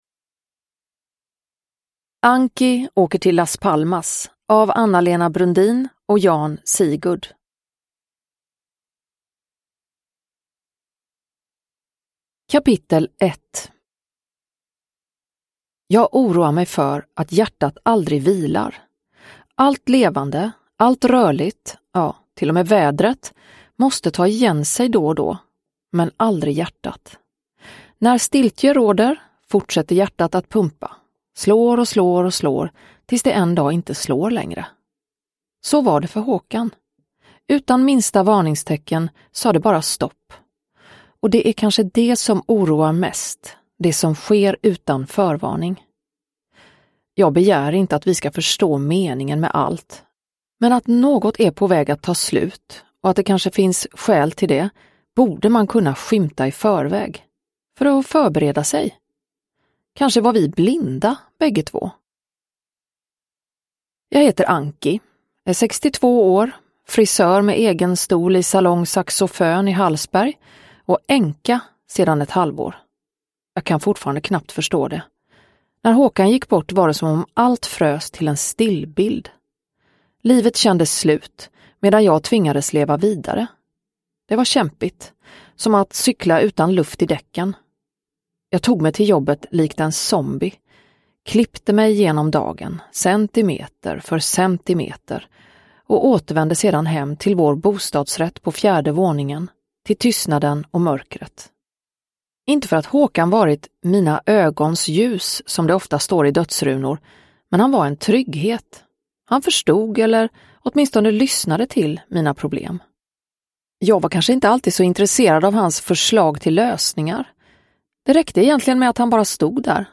Anki åker till Las Palmas – Ljudbok – Laddas ner
Uppläsare: Klara Zimmergren